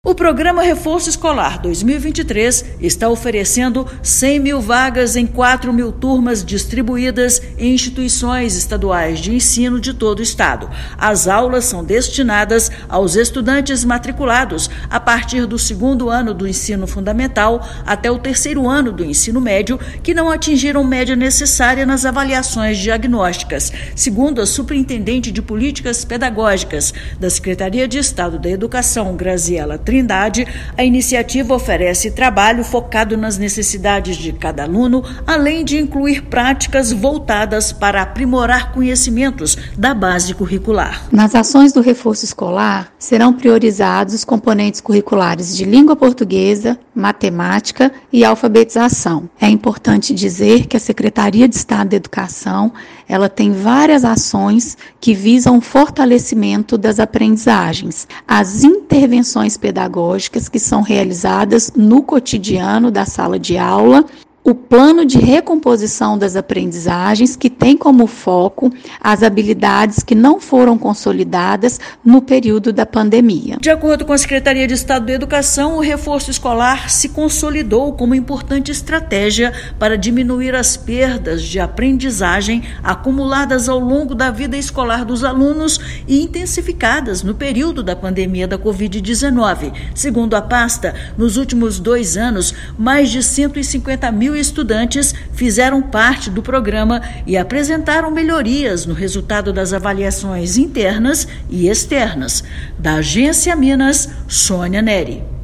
Aulas já começaram, mas estudantes público-alvo ainda podem participar da iniciativa disponibilizada em unidades de todo o estado. Ouça matéria de rádio.